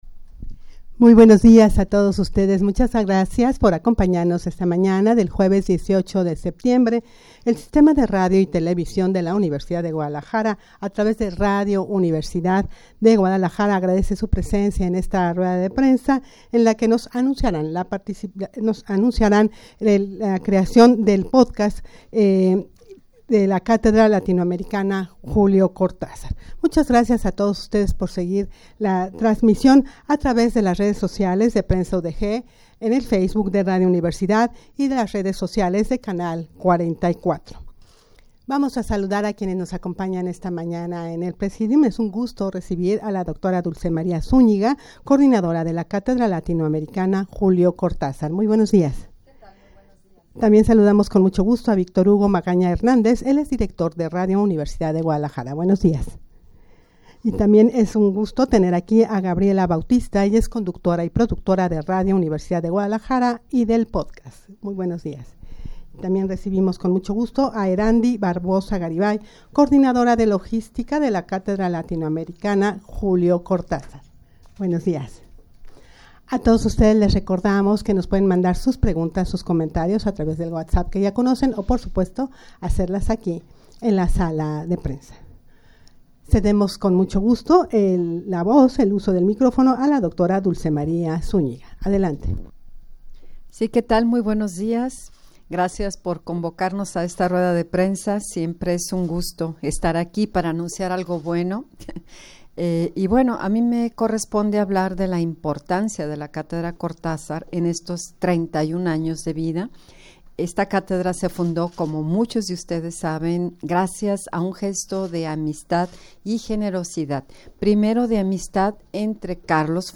Audio de la Rueda de Prensa
rueda-de-prensa-para-anunciar-la-realizacion-del-podcast-un-puente-es-una-persona-cruzando-un-puente.mp3